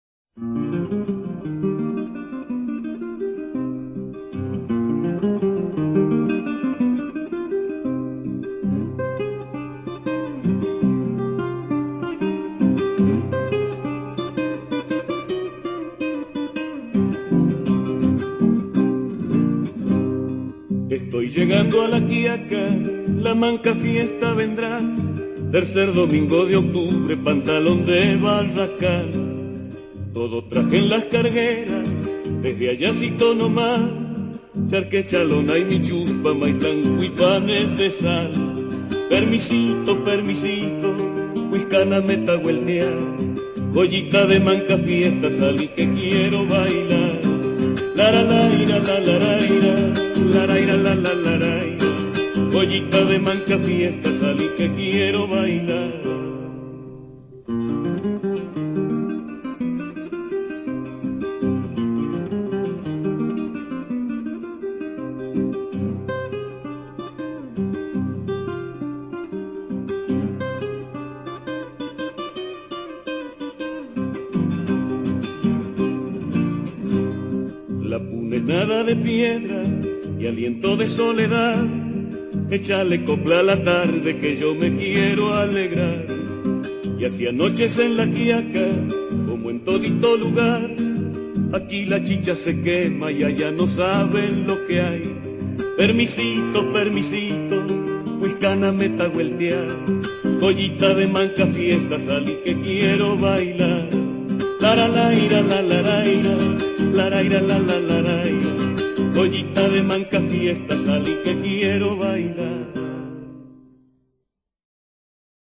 Se puede escuchar esa versión-por su autor
Bailecito